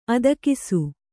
♪ adakisu